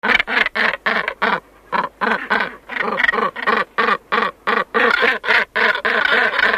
Głuptak - Morus bassanus
głosy